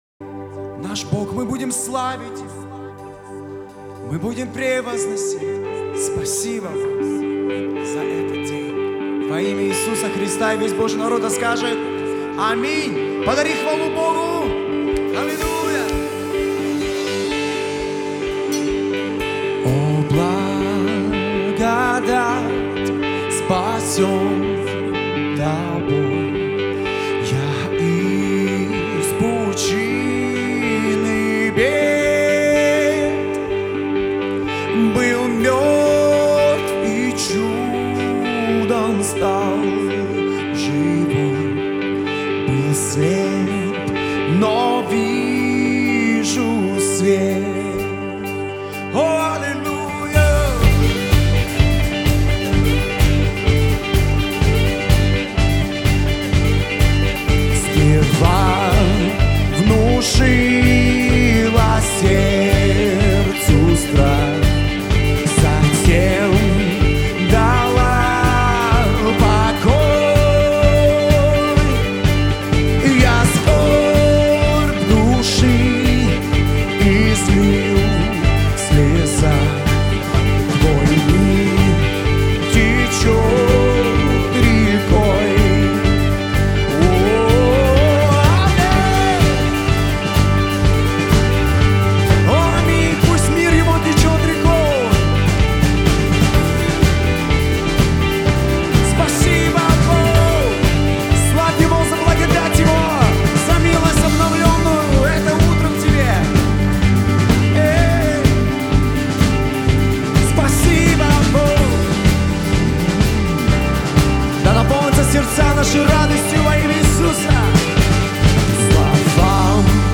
991 просмотр 188 прослушиваний 22 скачивания BPM: 126